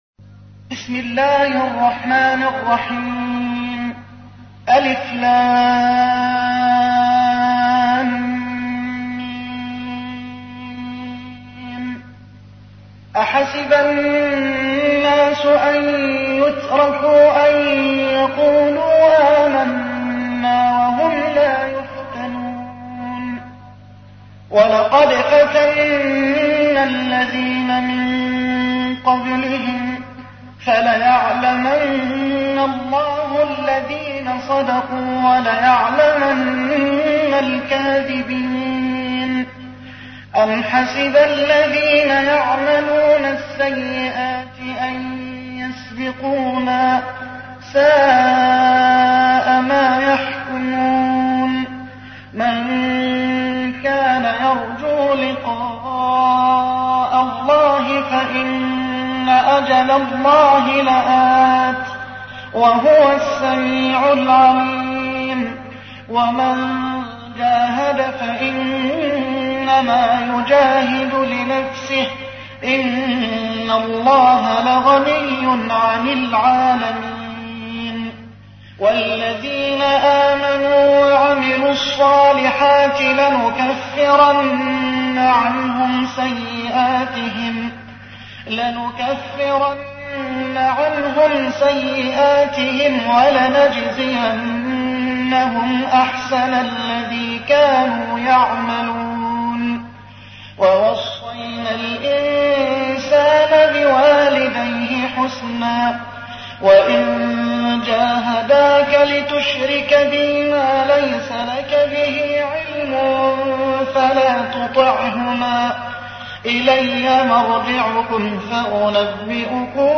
تحميل : 29. سورة العنكبوت / القارئ محمد حسان / القرآن الكريم / موقع يا حسين